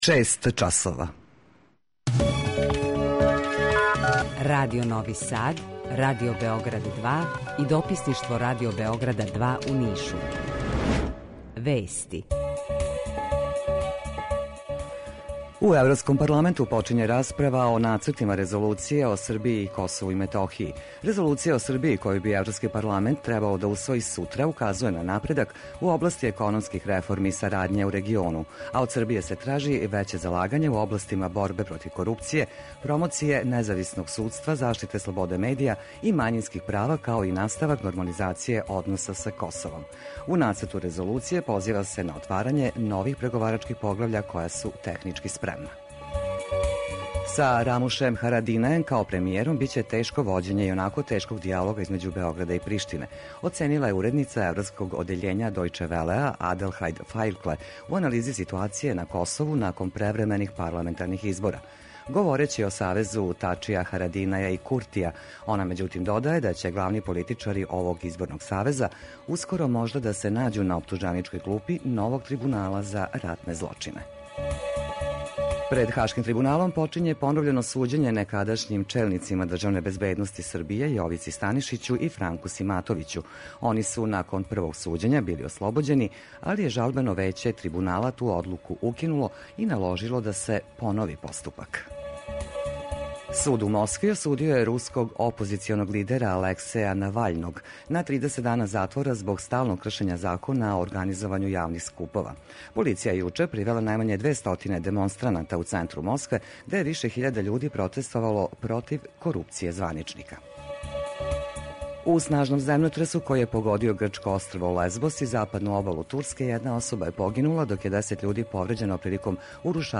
Jутарњи програм заједнички реализују Радио Београд 2, Радио Нови Сад и дописништво Радио Београда из Ниша.
У два сата, ту је и добра музика, другачија у односу на остале радио-станице.